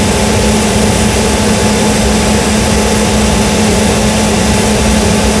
Sound jet.WAV